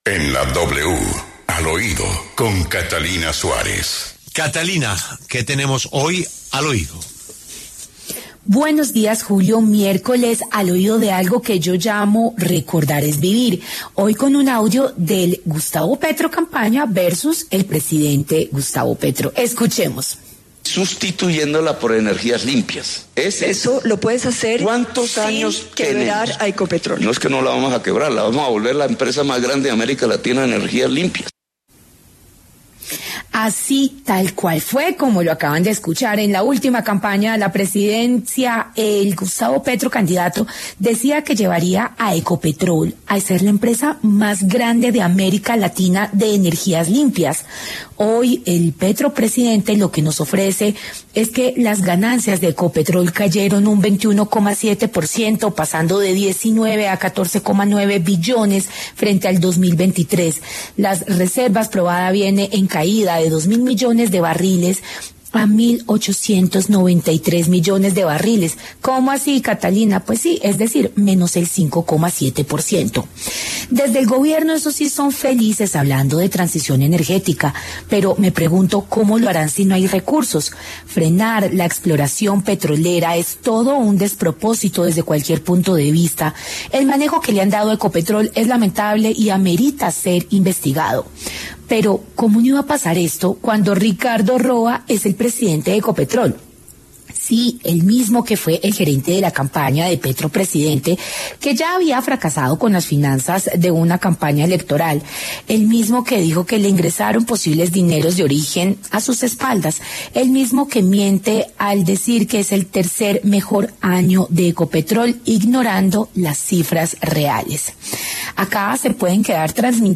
Al Oído le recuerda al presidente con un audio de campaña lo que habían prometido frente a Ecopetrol.